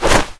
bird_flap_no_effect.ogg